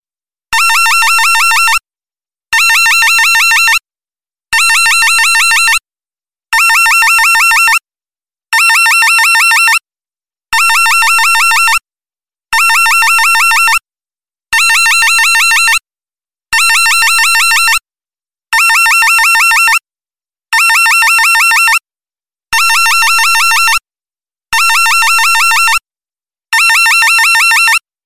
Рингтон Пронзительный звонок